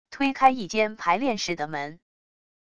推开一间排练室的门wav音频